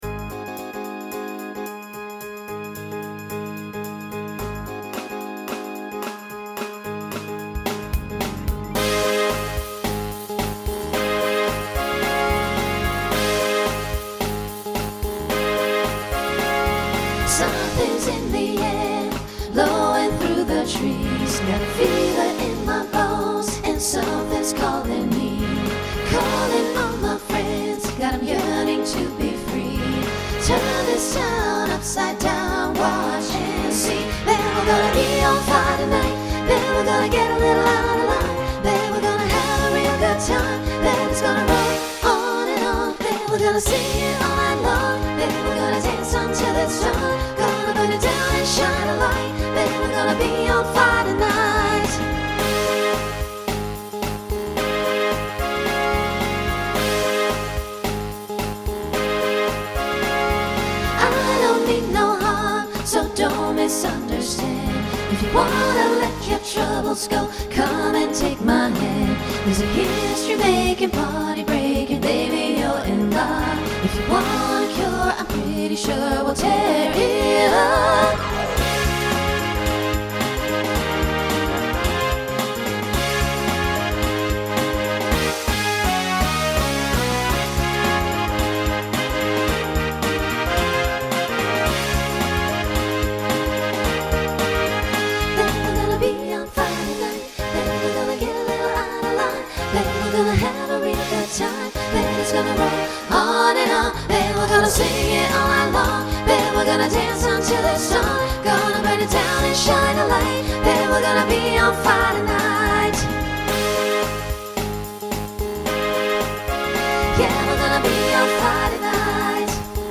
Voicing SATB Instrumental combo Genre Country